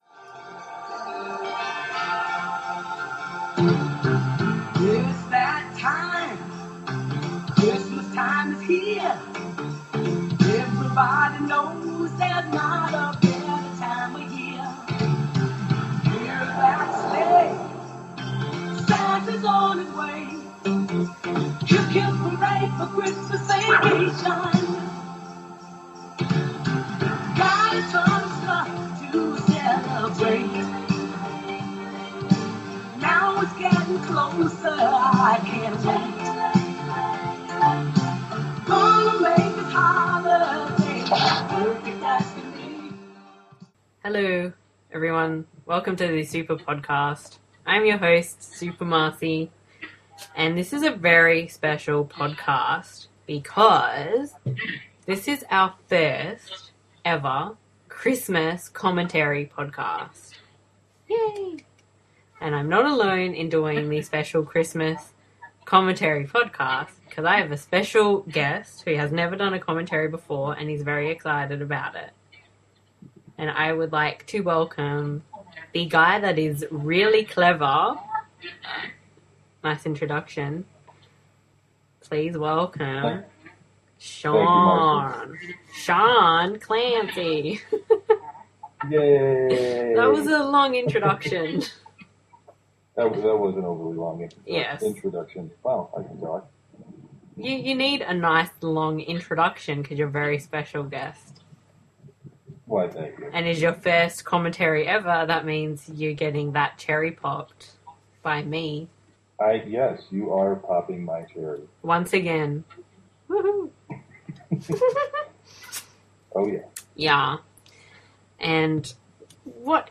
We’ve gone all out for you because we have our first Christmas themed Audio Commentary!